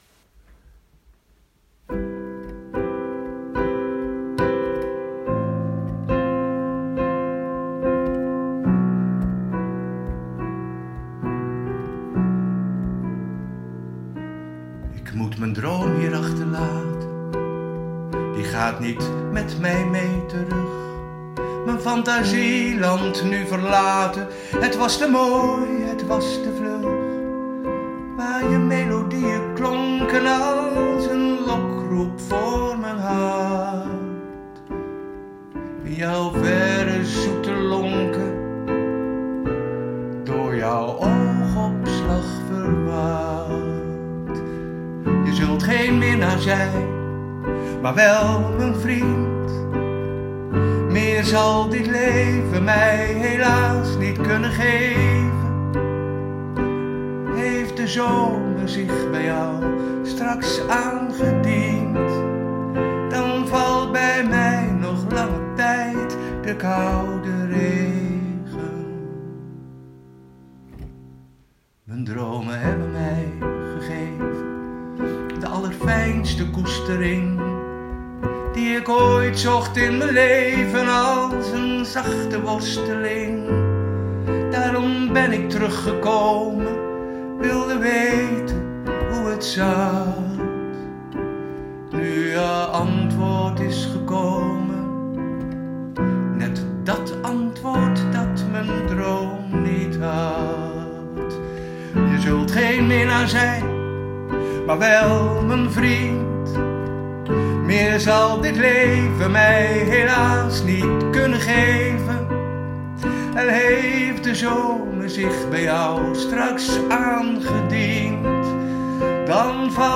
Een eenvoudige huisdemo laat je het liedje horen.